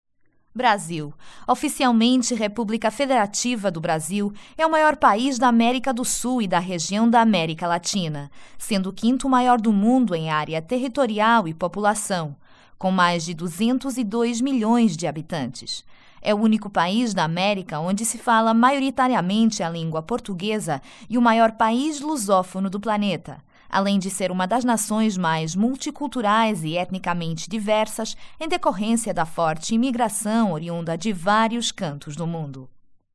✔ My guarantee: I have my own home studio.
Sprechprobe: Sonstiges (Muttersprache):